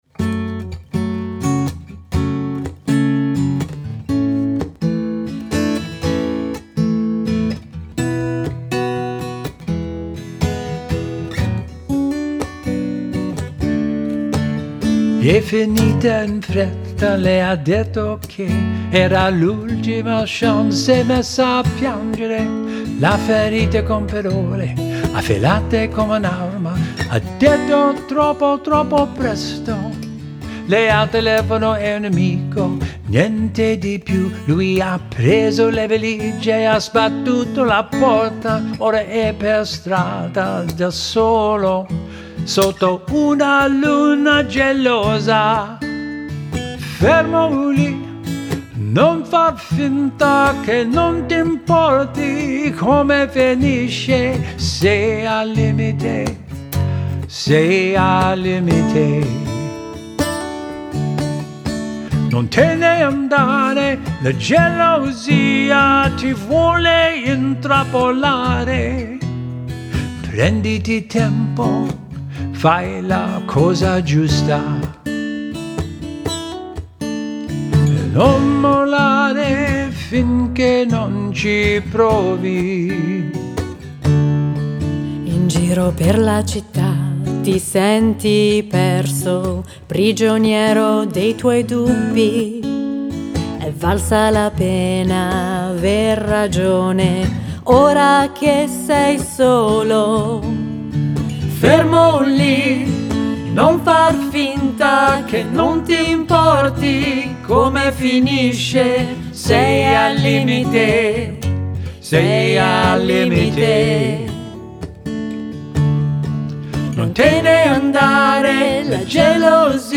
bring modern folk, acoustic blues